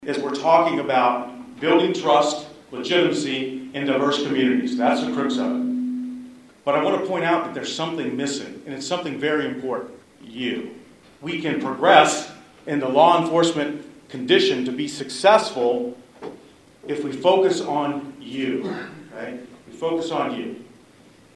The Riley County Law Board held a three hour public forum at the Manhattan Fire Department Headquarters.
Edwardsville Police Chief Mark Mathies spoke on the “Importance of You,” drawing from the origins of community policing and connecting it to modern day policing and building trust and legitimacy.